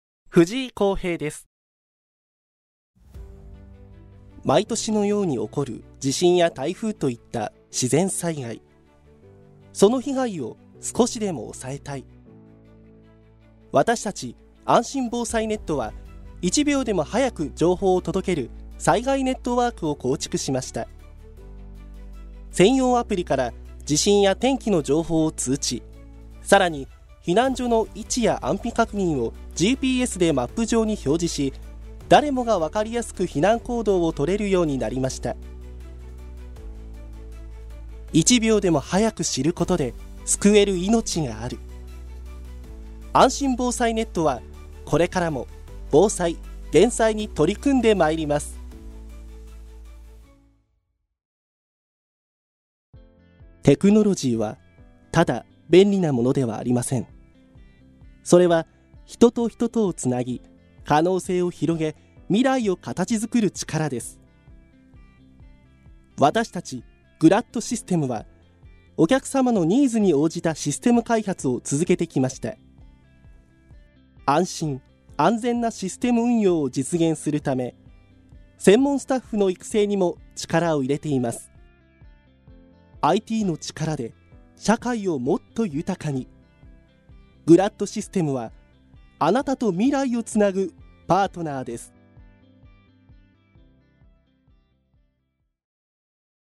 ボイスサンプル
• 明るく爽やかな声
• 音域：高～中音
• 声の特徴：さわやか、明るい
爽やか・ソフトな声質